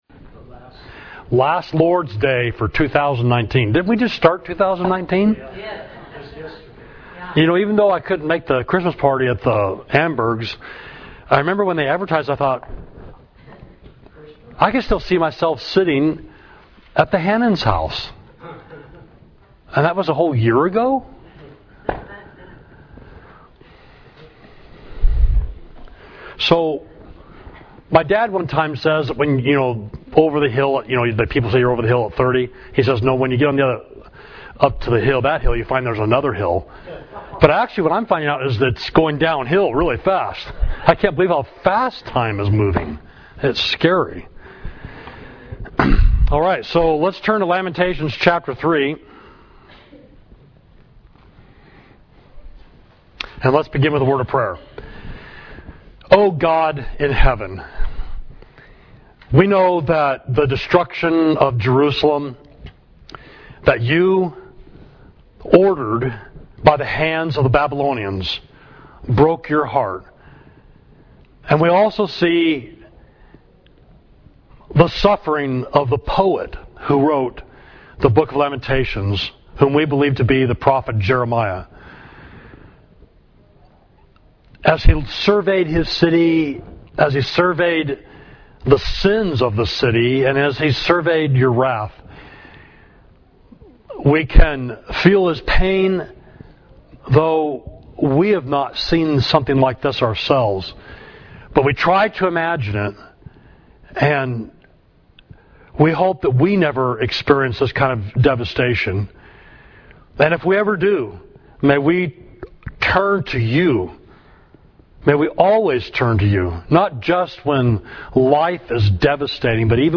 Class: Jeremiah’s Suffering and Hope, Lamentations 3